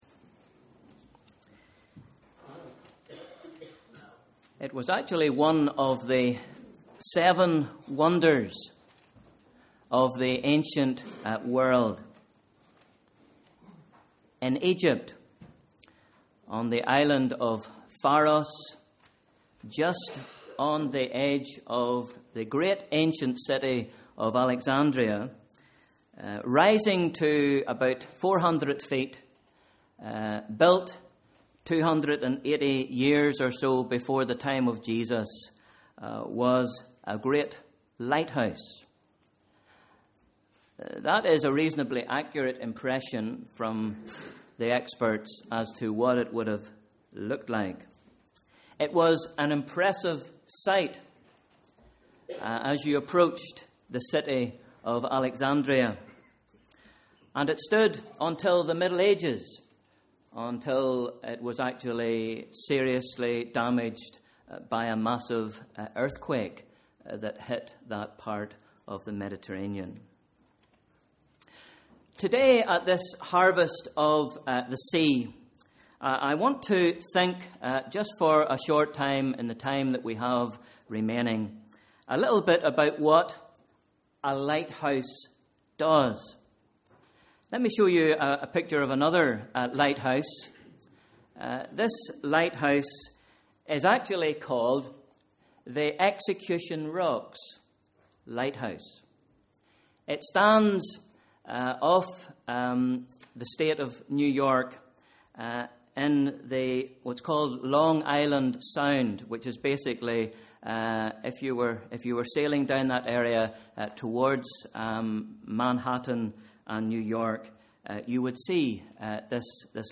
Harvest of the Sea – Sunday 1st December 2013